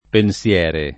pensiere [ pen SL$ re ]